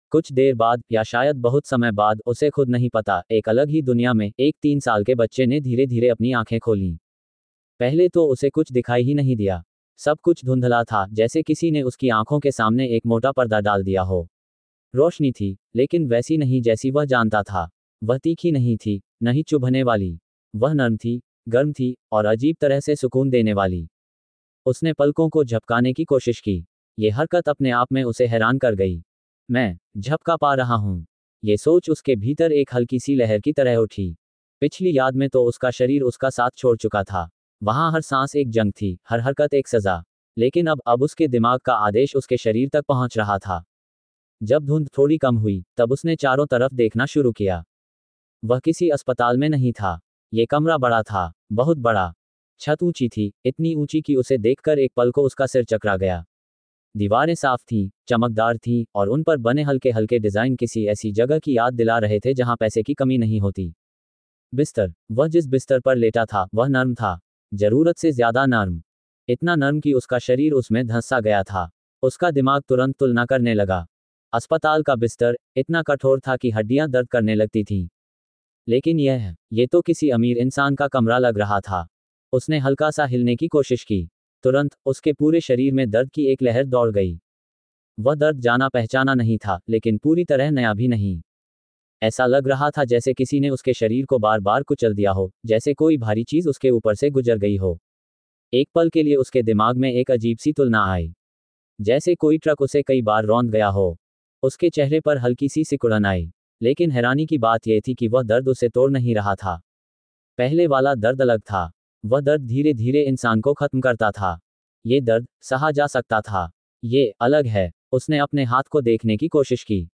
AudioTaleFM – Premium Sci-Fi, Fantasy & Fairy Tale Audio Stories